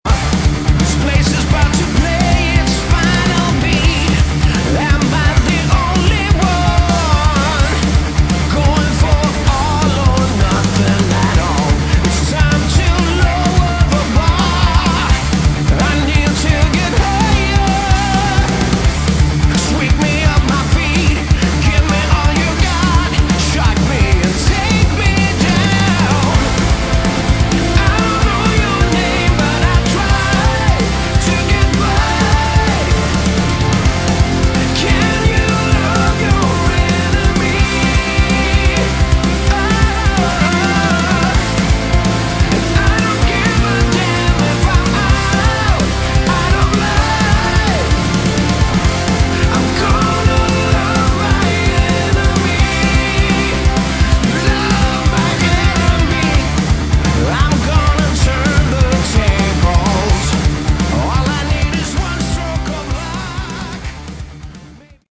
Category: Melodic Rock
Vocals, Bass
Drums
Keyboards
Guitars